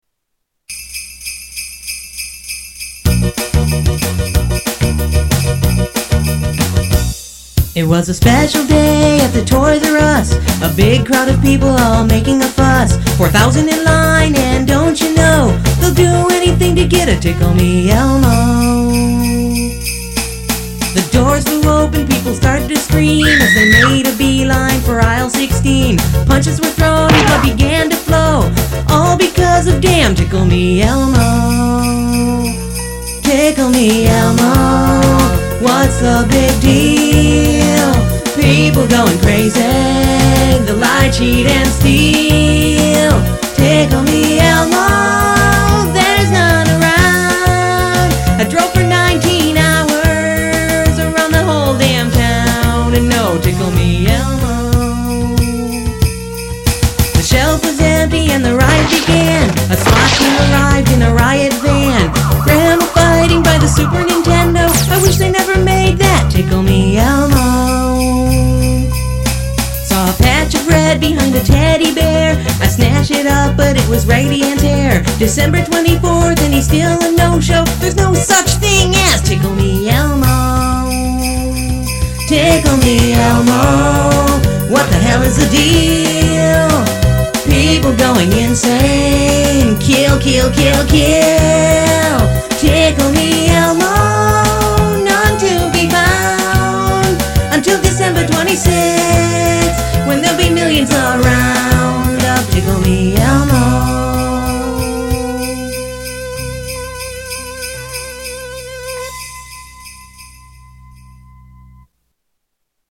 Parodies Songs Comedy Spoofs